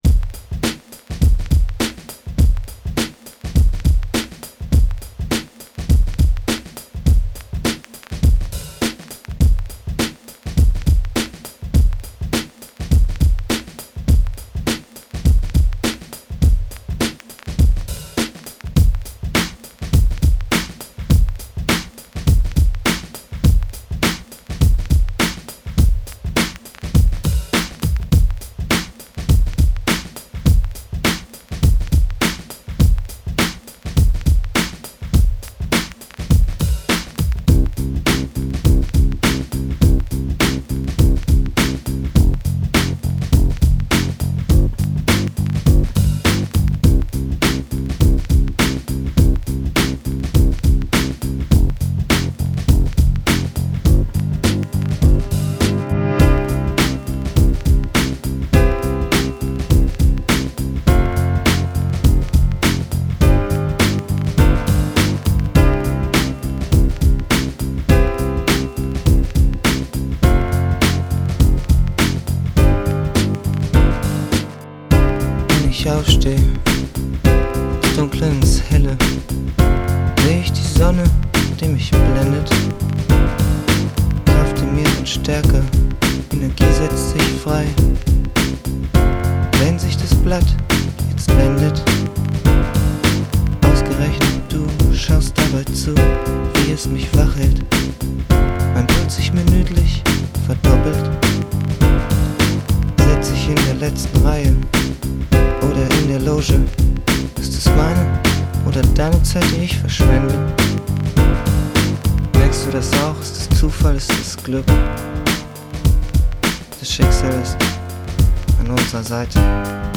RockPop/Mainstream
Alternative-Elektro-Breakbeat-TripHop-Tracks